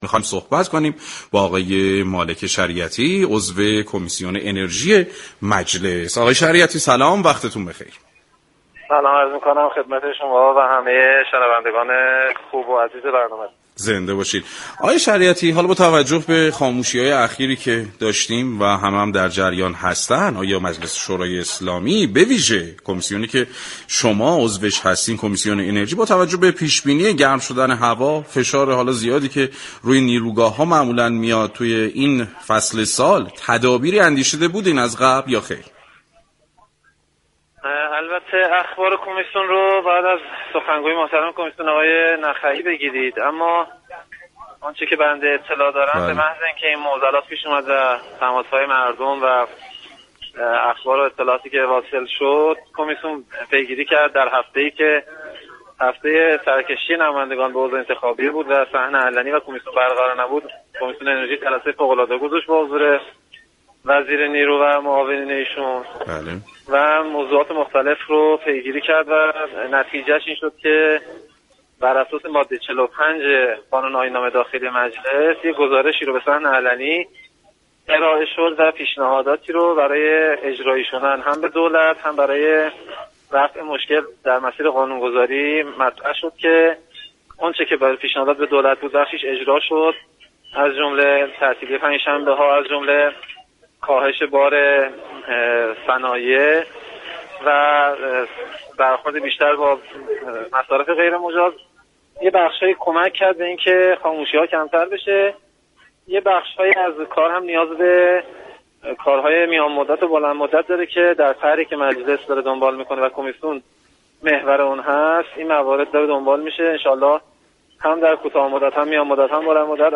به گزارش پایگاه اطلاع رسانی رادیو تهران، مالك شریعتی عضو كمیسیون انرژی مجلس در گفتگو با برنامه سعادت اباد رادیو تهران درباره تدابیر مجلس یازدهم پیرو خاموشی‌های اخیر با اشاره به برگزاری جلسه فوق العاده كمیسیون انرژی با حضور وزیر نیرو و معاونانش اظهار داشت: در این جلسات برخی تصمیمات اتخاذ شد و بر اساس ماده 45 قانون آیین نامه داخلی مجلس، گزارشی به صحن علنی ارائه و پیشنهاداتی مطرح شد.